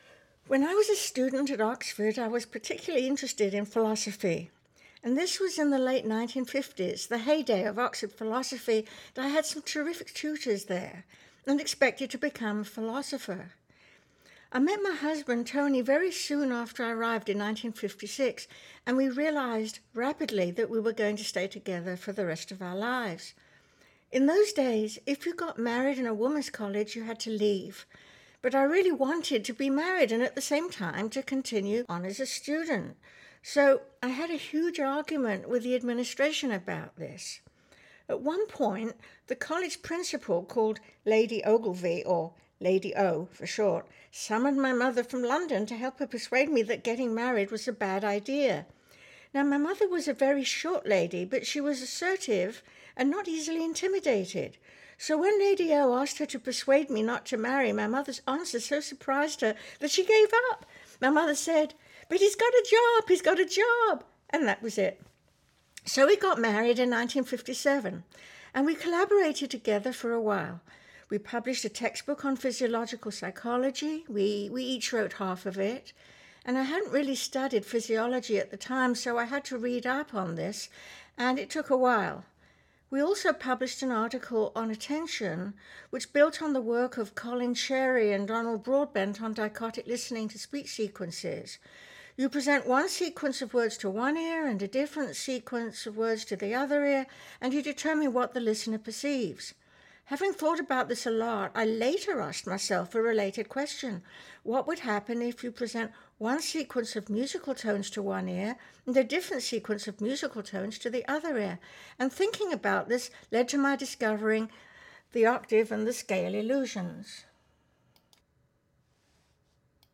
In the next narrative Dr. Deutsch describes her experience at St. Anne’s including how — through the force of her mother’s personality – she was able to break one of the restrictions they had enforced on young women and still graduate!